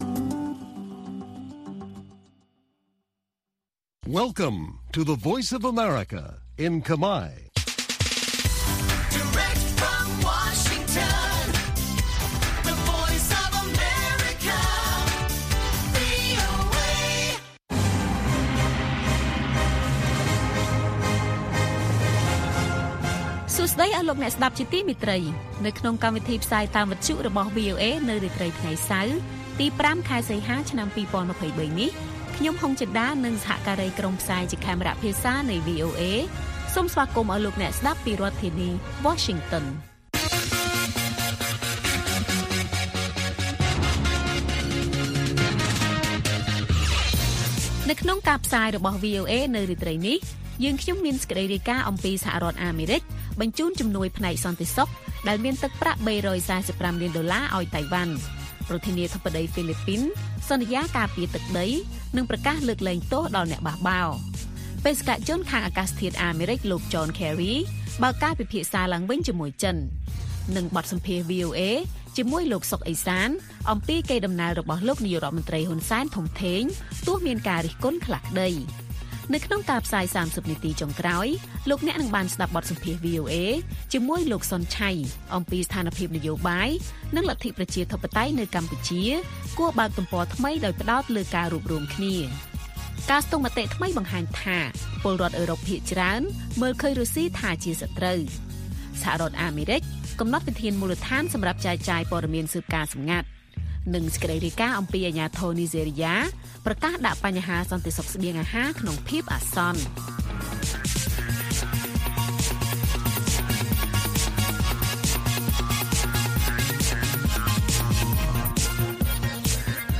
ព័ត៌មានថ្ងៃនេះមានដូចជា សហរដ្ឋអាមេរិកបញ្ជូនជំនួយផ្នែកសន្តិសុខ ដែលមានទឹកប្រាក់ ៣៤៥ លានដុល្លារឱ្យតៃវ៉ាន់។ បទសម្ភាសន៍ VOA ជាមួយលោក សុន ឆ័យ អំពីស្ថានភាពនយោបាយនិងលទ្ធិប្រជាធិបតេយ្យនៅកម្ពុជាគួរបើកទំព័រថ្មីដោយផ្តោតលើការរួបរួមគ្នា និងព័ត៌មានផ្សេងៗទៀត។